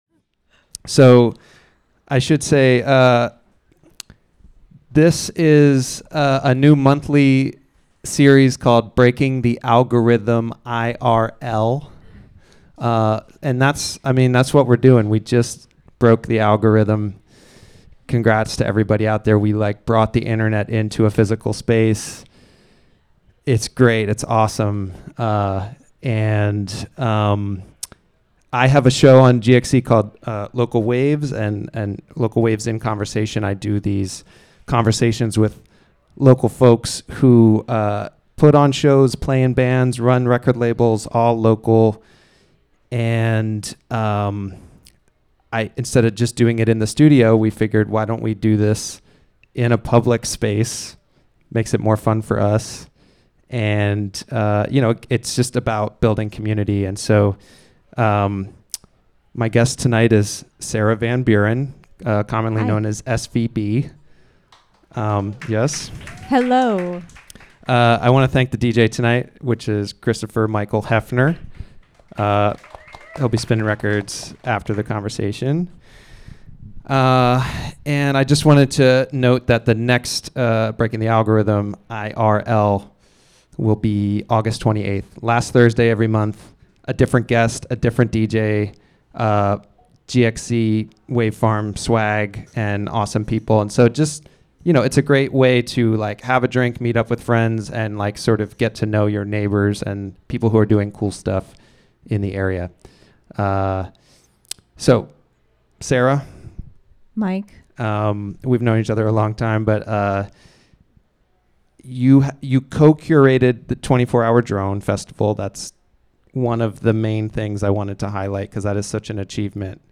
sits down for a short discussion with local musicians, record labels, recording engineers, venue owners, and more to dig deeper into the undercurrent of activity.